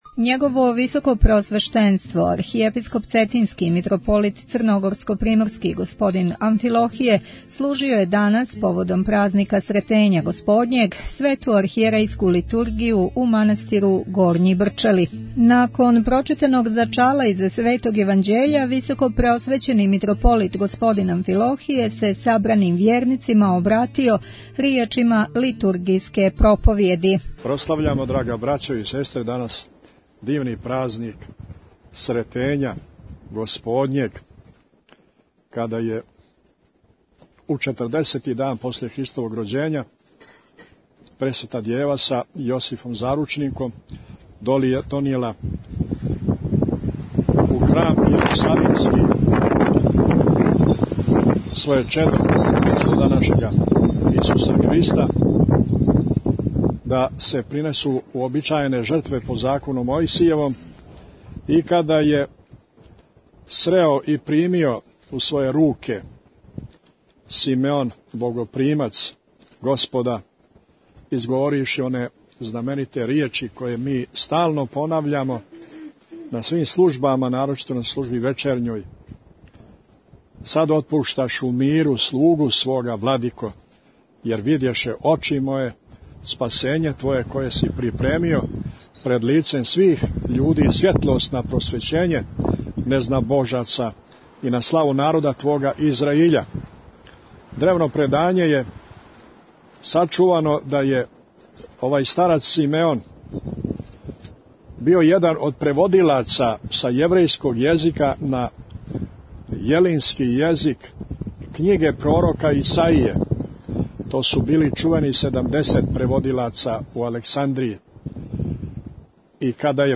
Бесједе